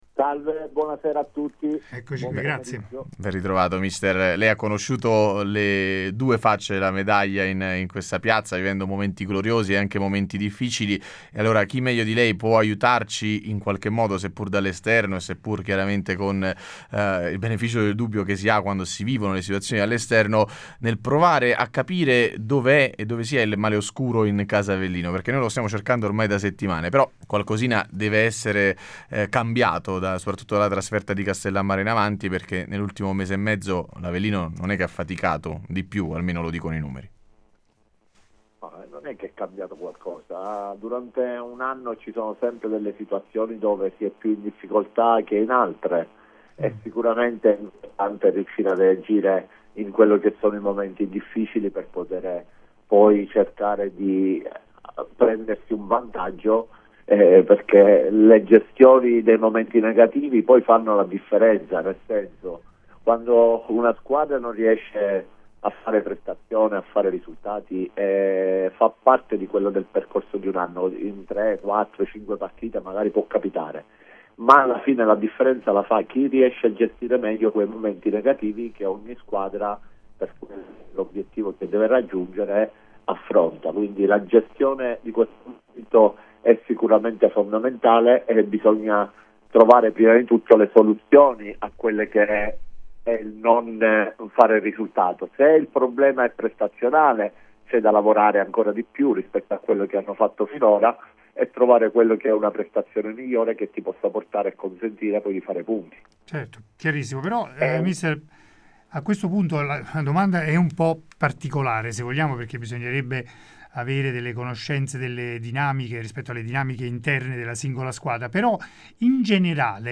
In diretta a Il Pomeriggio da Supereroi di Radio Punto Nuovo